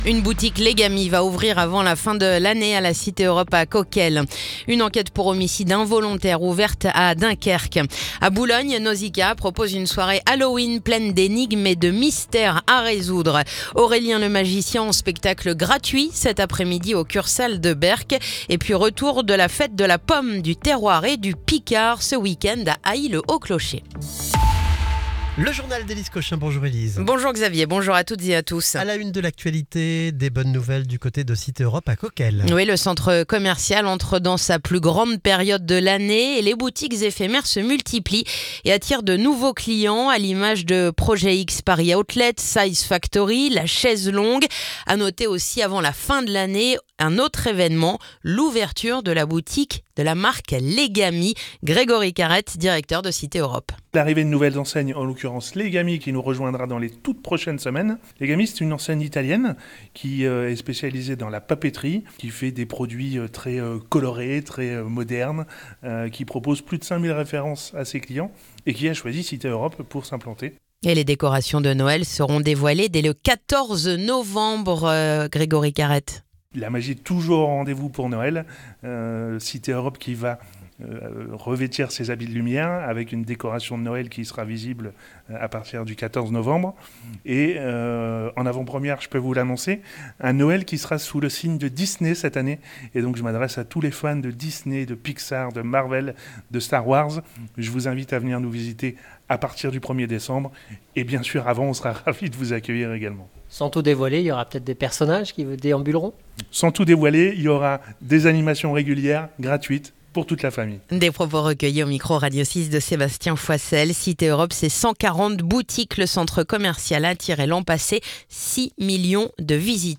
Le journal du mardi 28 octobre